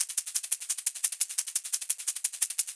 Shaker Loops (1).wav